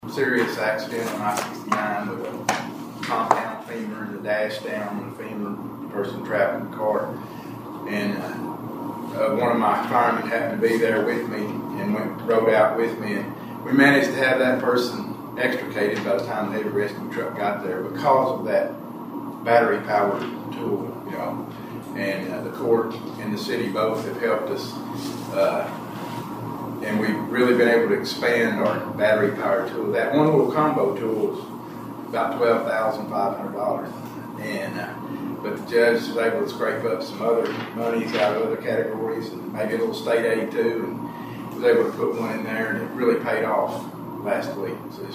Magistrates in Caldwell County learned about significant upgrades to local emergency equipment—specifically, battery-powered rescue tools—during this week’s Fiscal Court meeting.